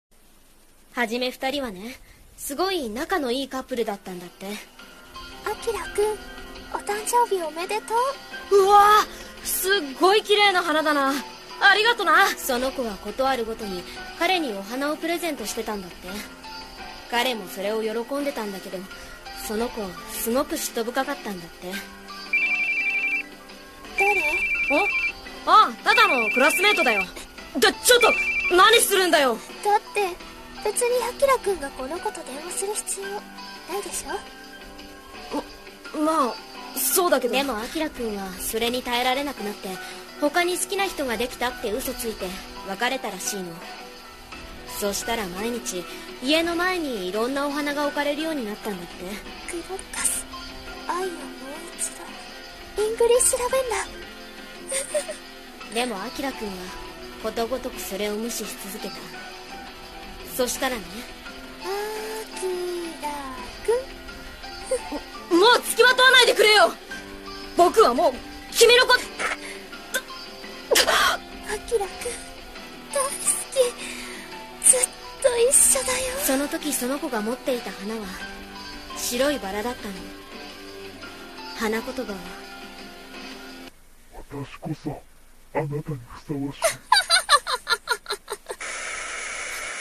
【コラボ声劇】花言葉の呪い【ヤンデレ】